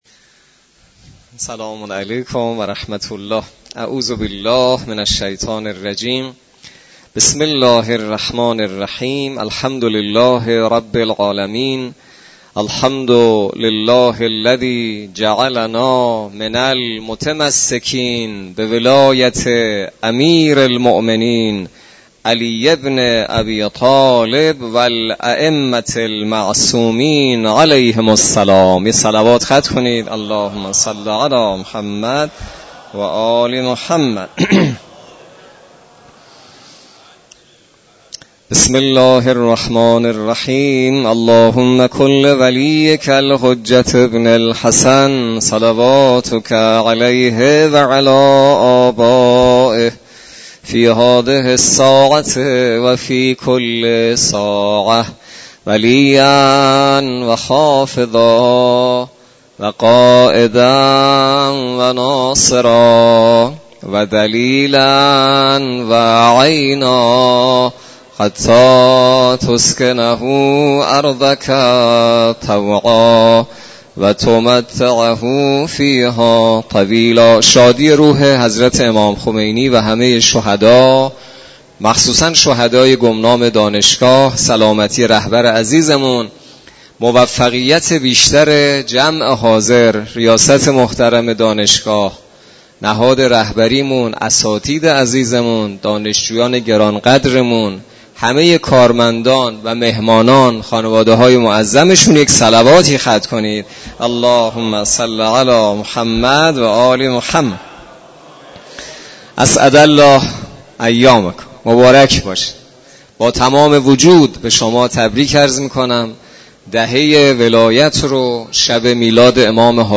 برگزاری شادیانه عید خجسته غدیر همراه با جلسه تفسیر قرآن توسط نماینده محترم ولی فقیه در مسجد دانشگاه کاشان